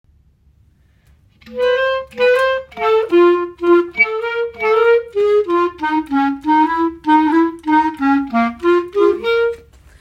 Klarinetti
Klarinetti.m4a